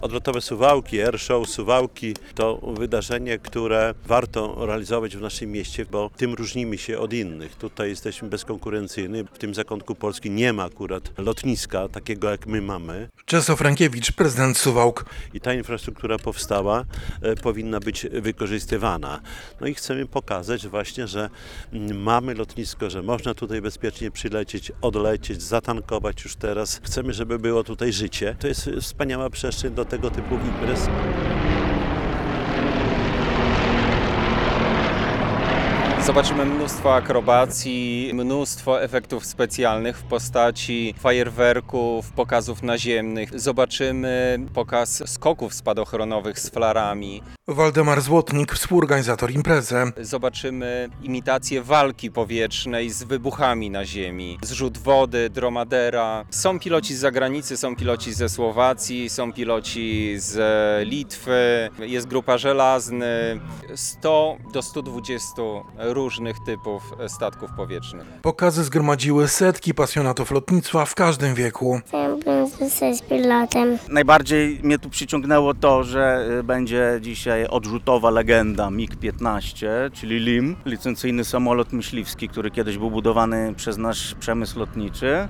Ponad sto samolotów i śmigłowców można zobaczyć na imprezie "Odlotowe Suwałki" Air Show 2023, która odbywa się na suwalskim lotnisku.
- To impreza, którą organizujemy zarówno jako atrakcję dla mieszkańców i turystów, jak i promocję naszego lotniska, które jest wyjątkowe w tej części kraju, bo jedyne tej wielkości i z taką infrastrukturą - mówi prezydent Suwałk Czesław Renkiewicz.
- Mnie tu najbardziej interesuje odrzutowa legenda, czyli samolot myśliwski MIG-15, który kiedyś na licencji był budowany przez nas przemysł lotniczy - mówi jeden z pasjonatów.